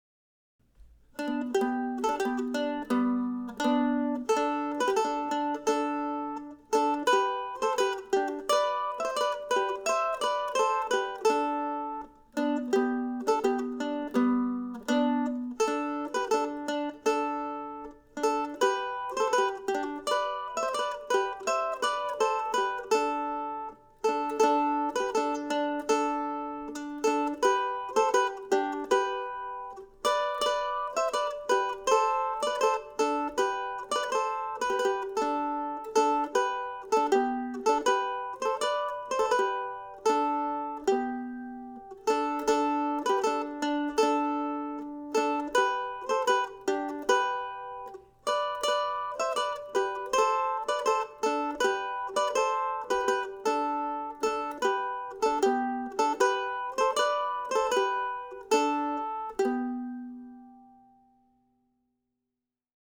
Here also is no. 7 of William Bates' duettinos, adapted for the mandolin.